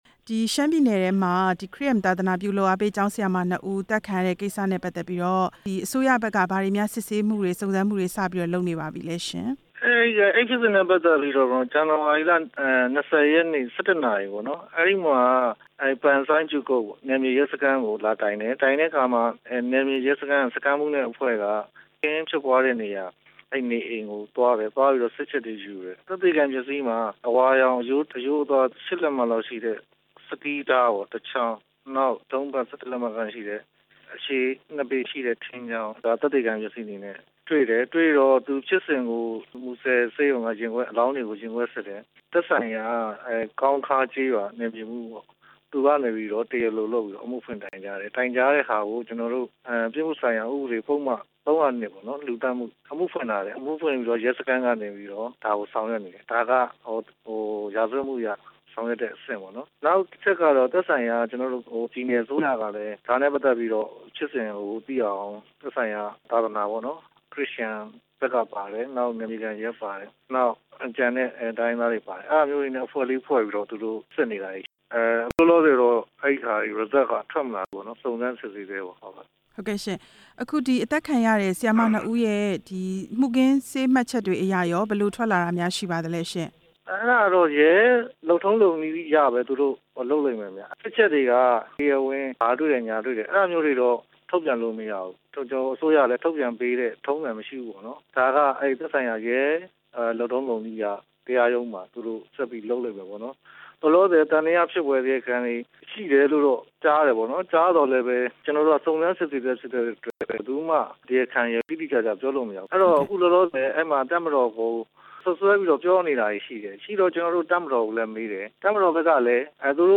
ဦးဇော်ဌေးကို မေးမြန်းချက်